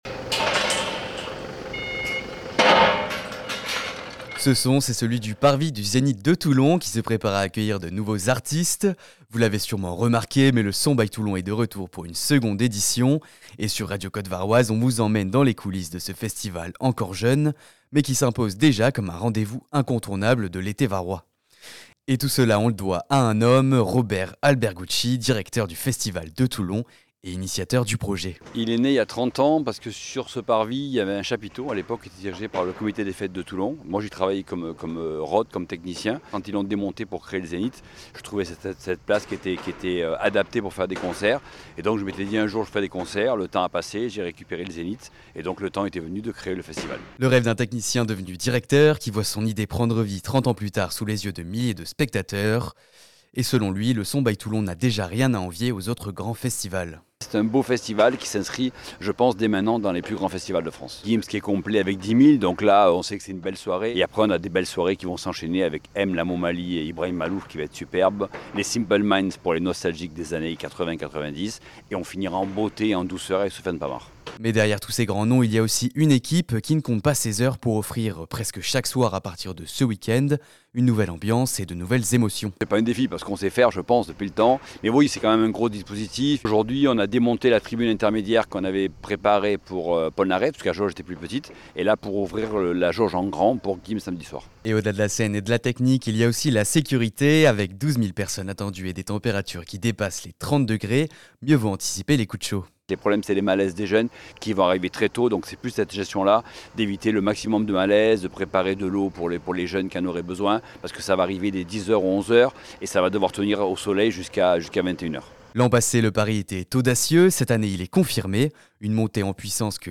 Reportage au coeur des coulisses du festival 100% Toulon, Le Son By Toulon.